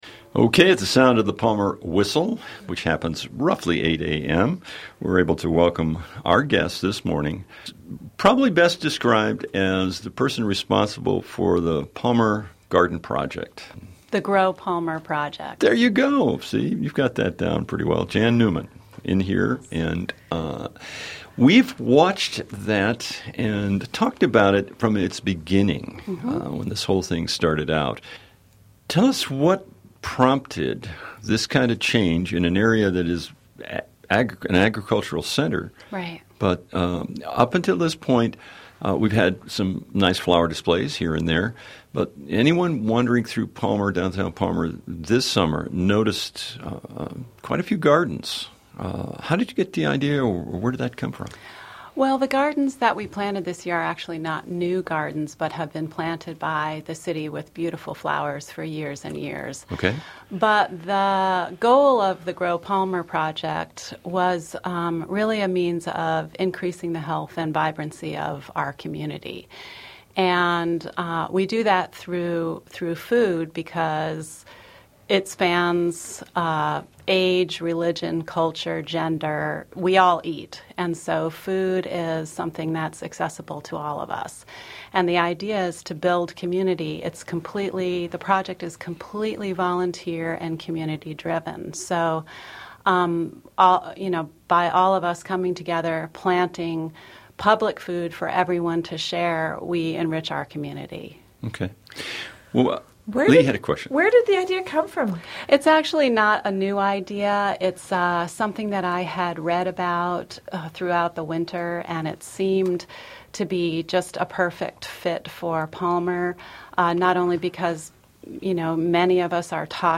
Grow Palmer: Interview